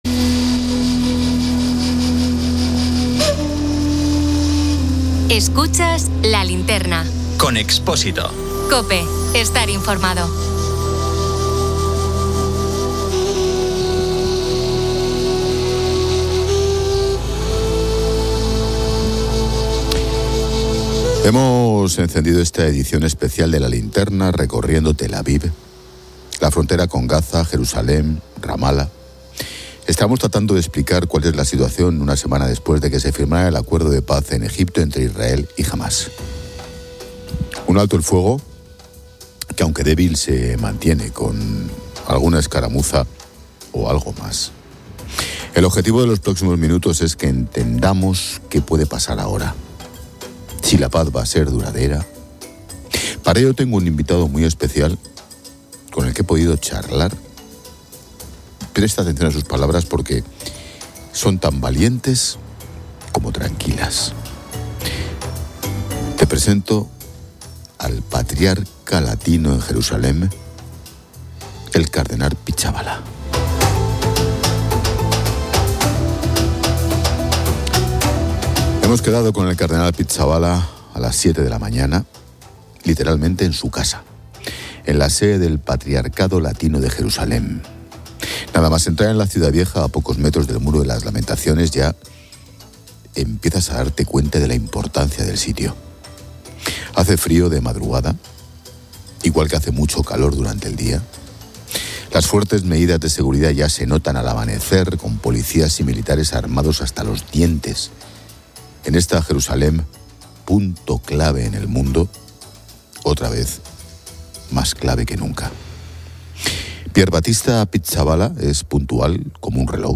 El programa especial de LA LINTERNA se emite desde Jerusalén, Tel Aviv, Ramallah y la frontera de Gaza, donde se analiza la situación una semana después del acuerdo de paz entre Israel y Hamas.